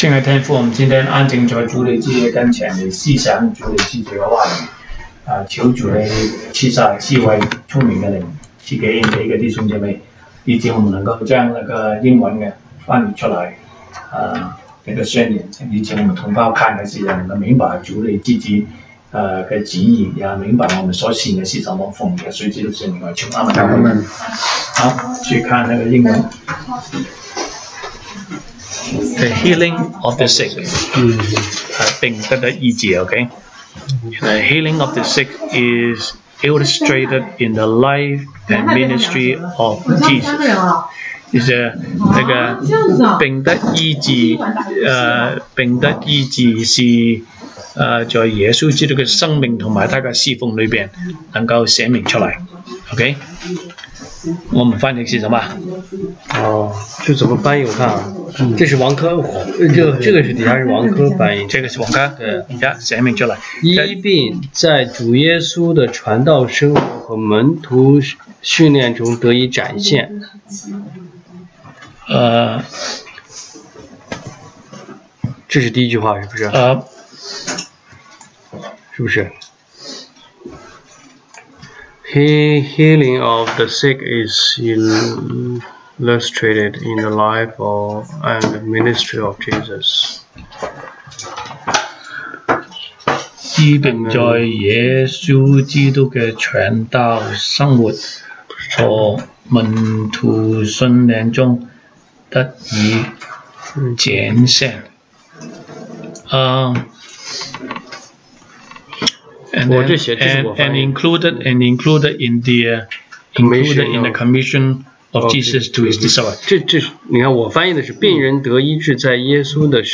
週一國語研經 Monday Bible Study « 西堂證道(粵語/國語